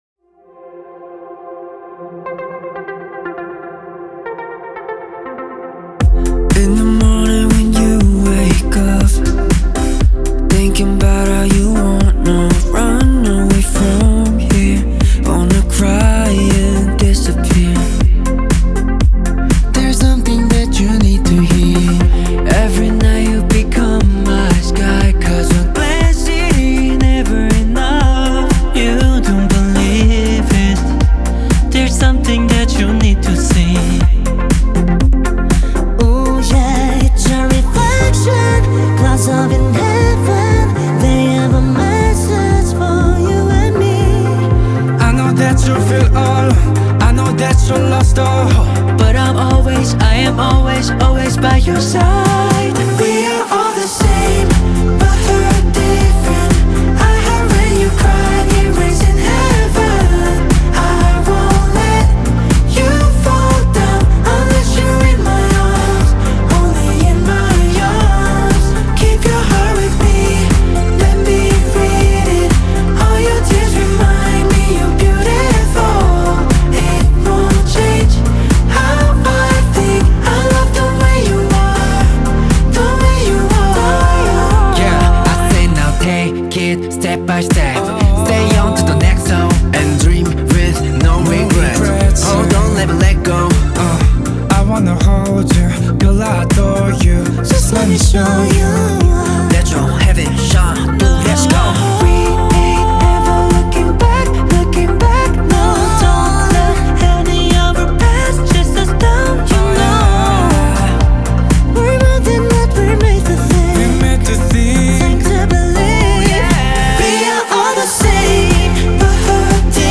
Азиатские хиты